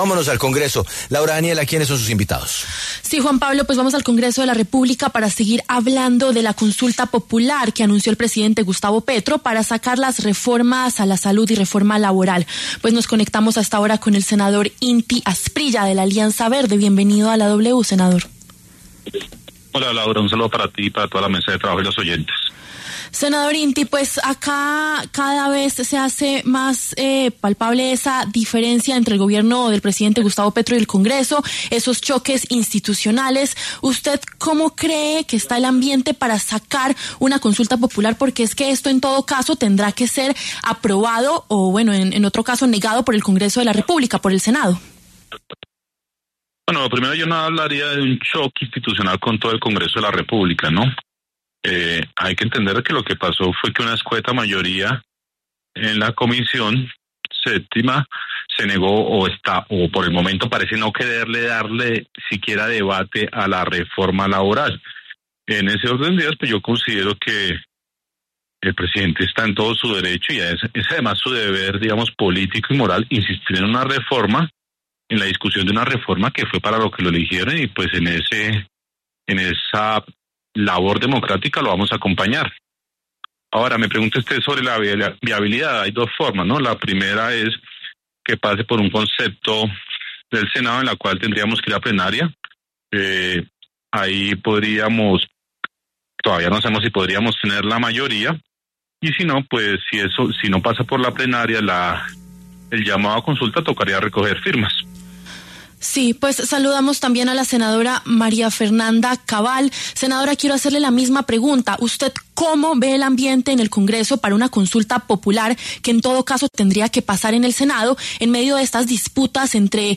Los senadores Inti Asprilla, de la Alianza Verde, y María Fernanda Cabal, del Centro Democrático, hablaron en La W.
En diálogo con W Radio, los senadores Inti Asprilla, de la Alianza Verde, y María Fernanda Cabal, del Centro Democrático, debatieron sobre la viabilidad de la consulta y los obstáculos que enfrentaría en su eventual llegada al legislativo.